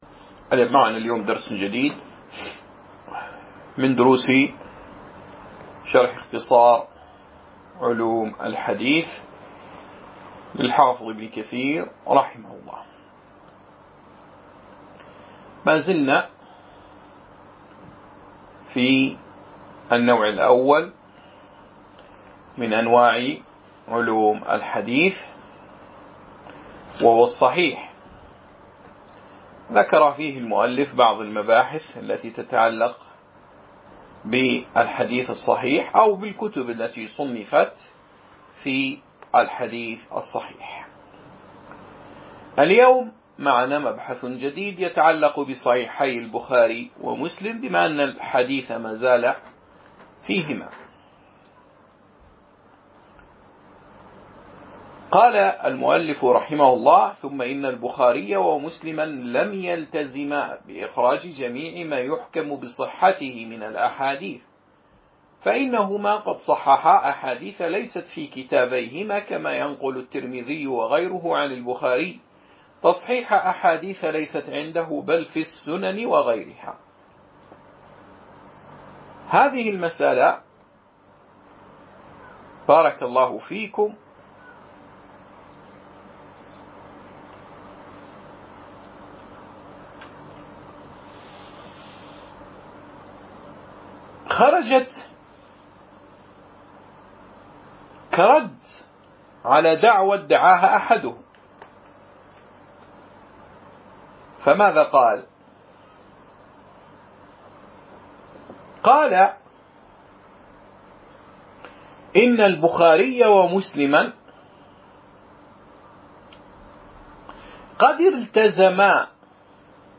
شرح اختصار علوم الحديث الدرس 6